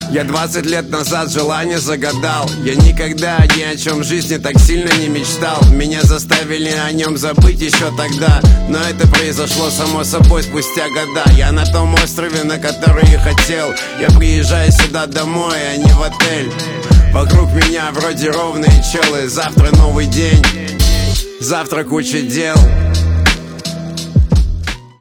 русский рэп
пианино